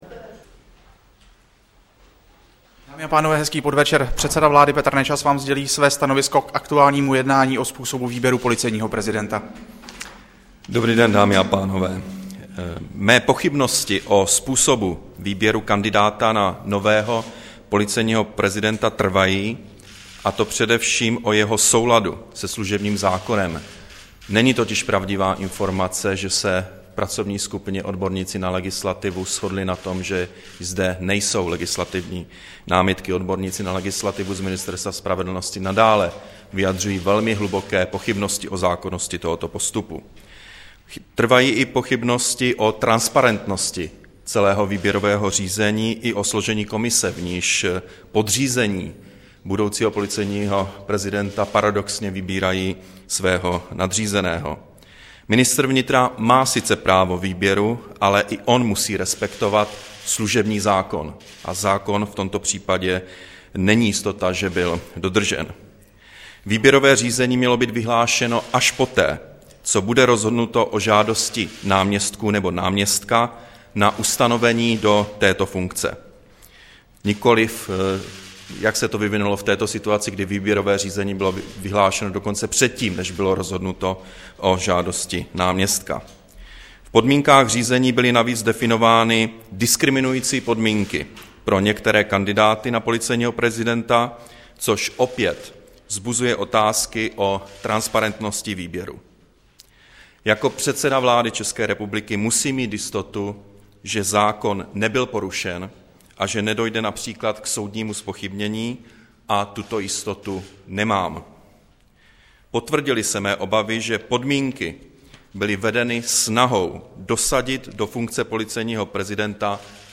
Tiskové prohlášení premiéra k výběru policejního prezidenta, 19. ledna 2011